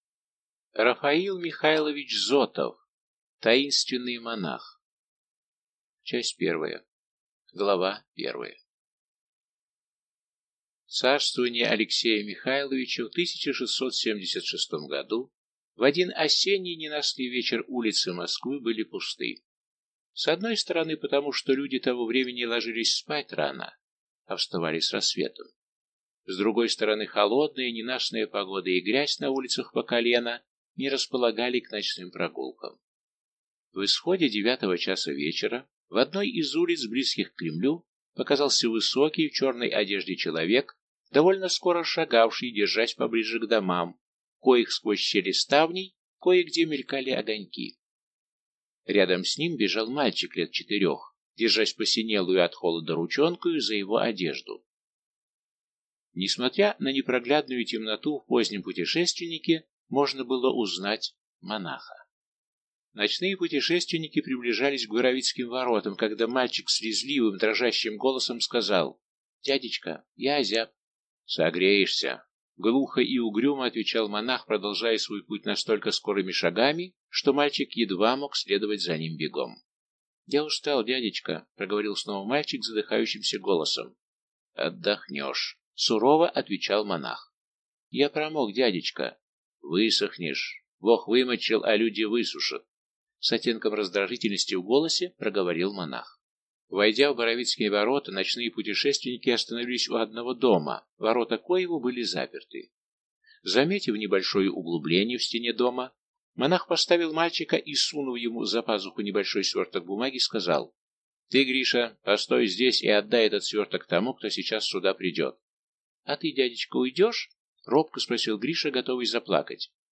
Аудиокнига Таинственный монах | Библиотека аудиокниг